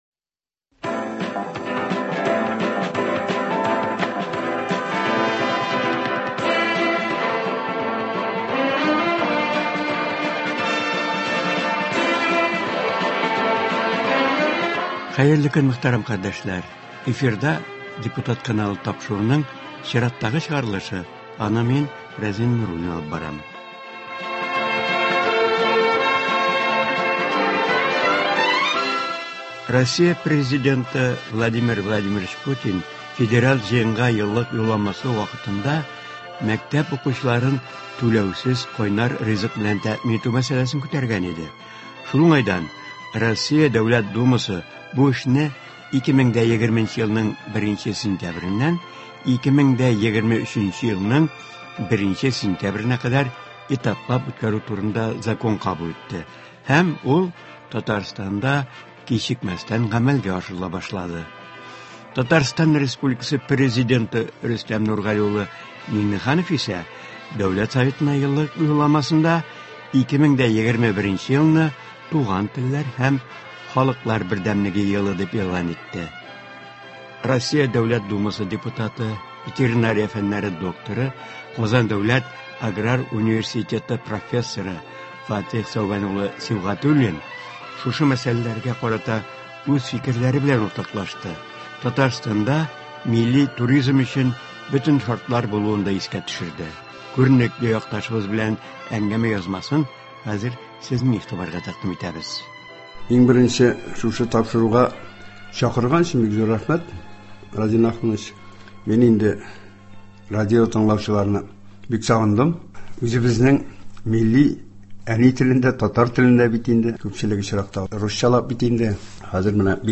Россия Дәүләт Думасы депутаты, ветеринария фәннәре докторы, Казан дәүләт аграр университеты профессоры Фатыйх Сәүбән улы Сибгатуллин шушы мөһим мәсьәләләргә карата үз фикерләре белән уртаклашты, Татарстанда милли туризм өчен бөтен шартлар булуын искә төшерде.